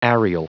Prononciation du mot areal en anglais (fichier audio)
Prononciation du mot : areal